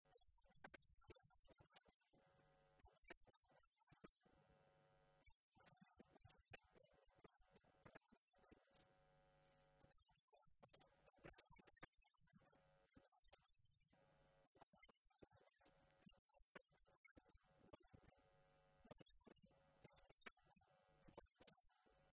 AUDIO : Diputado Daniel Manouchehri